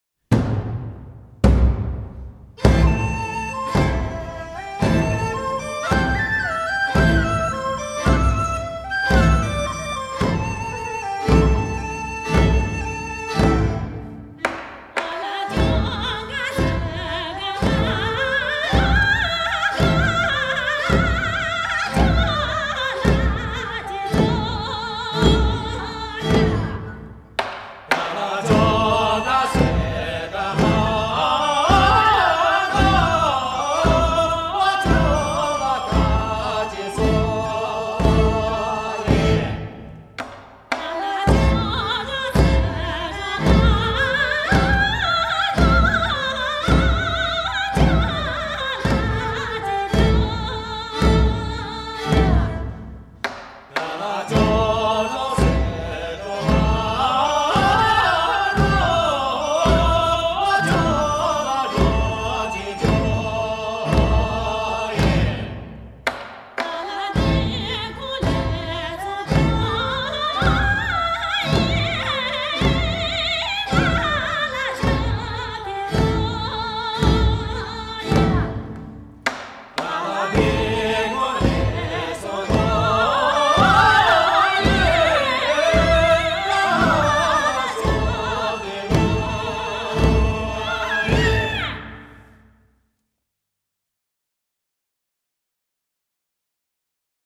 少数民族音乐系列
29首歌，旋律朴实悦耳，歌声高吭开怀，
充份展现厡野牧民和农村纯朴，直率奔放的民风。
都有嘹亮的歌声，一流的技巧。